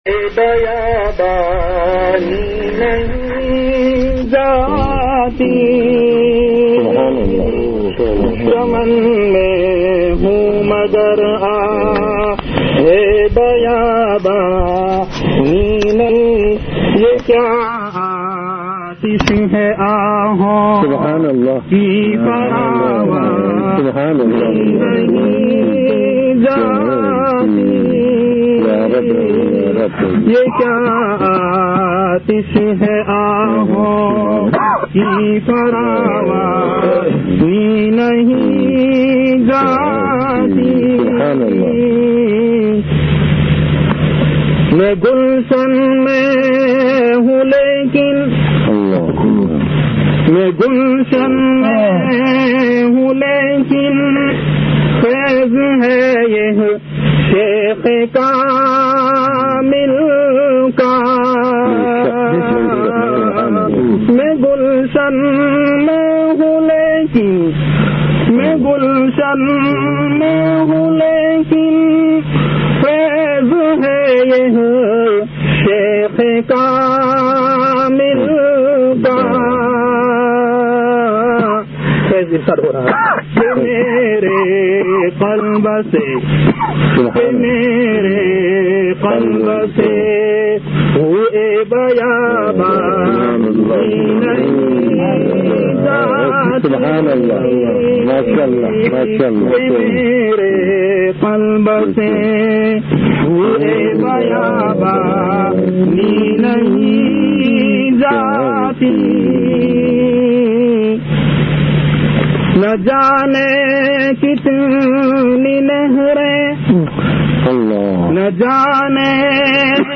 An Islamic audio bayan by Hazrat Maulana Shah Hakeem Muhammad Akhtar Sahab R.A on Bayanat. Delivered at Khanqah Imdadia Ashrafia.